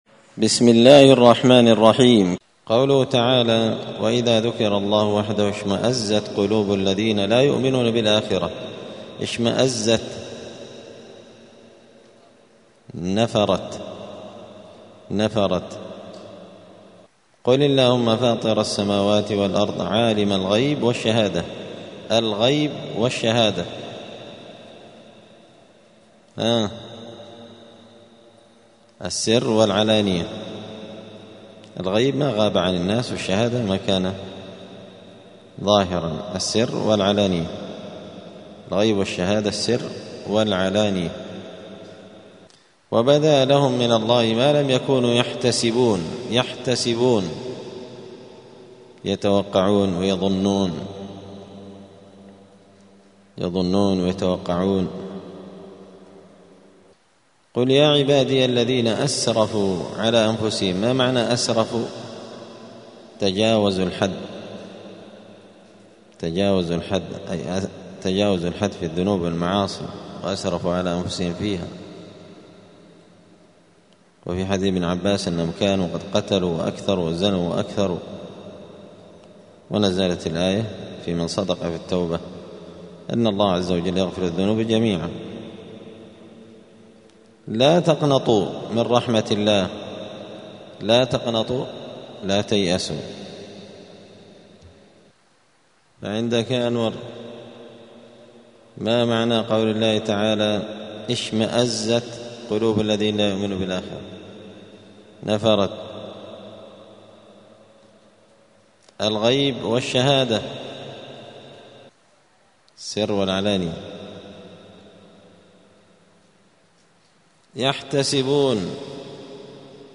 *(جزء الزمر سورة الزمر الدرس 258)*